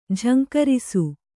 ♪ jhaŋkarisu